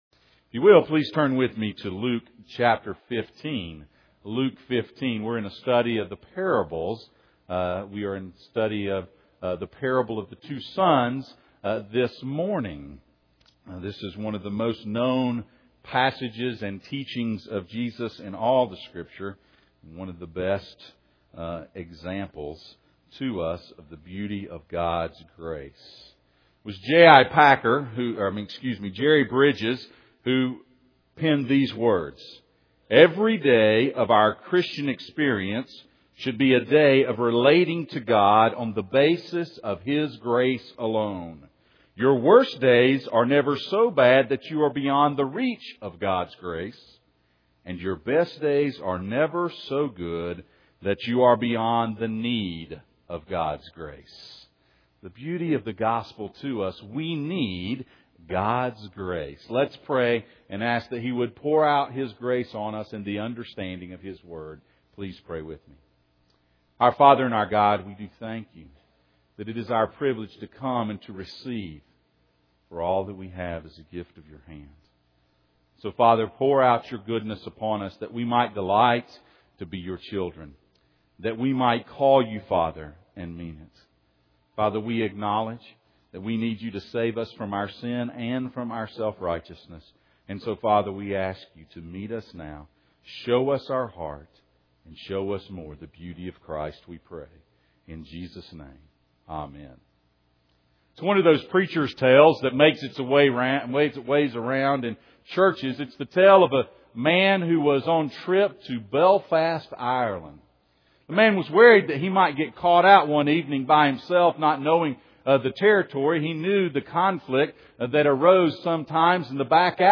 Kingdom Discipleship Passage: Luke 15:1-2, Luke 15:11-32 Service Type: Sunday Morning « Lost and Found